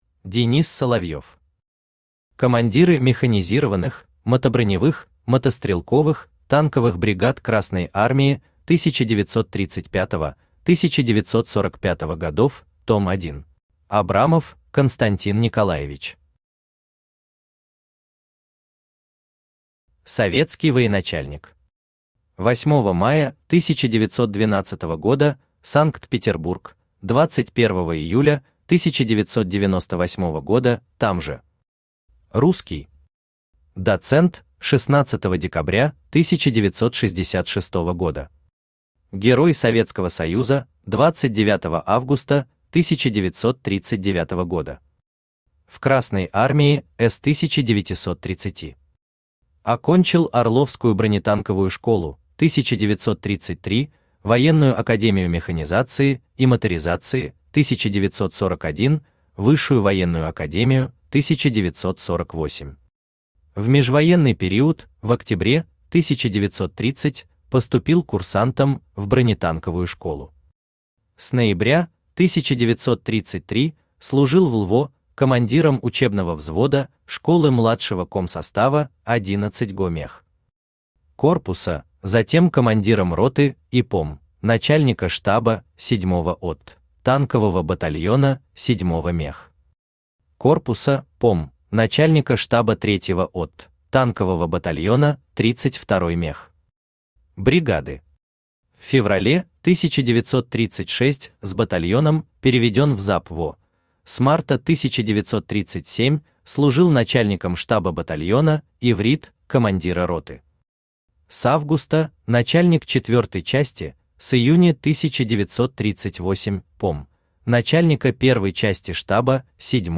Аудиокнига Командиры механизированных, мотоброневых, мотострелковых, танковых бригад Красной Армии 1935-1945 гг. Том 1 | Библиотека аудиокниг